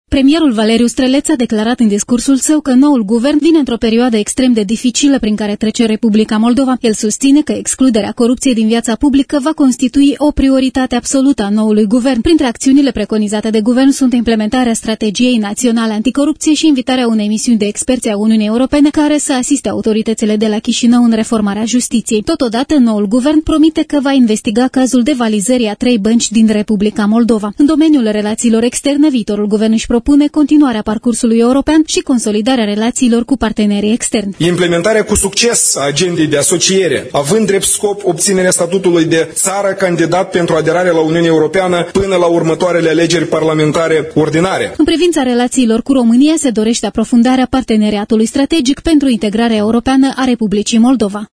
De la Chișinău, relatează